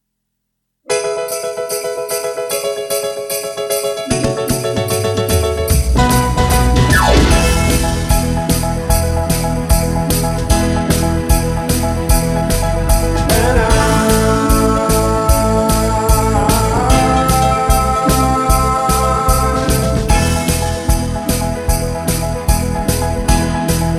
Three Semitones Down Pop (1990s) 3:50 Buy £1.50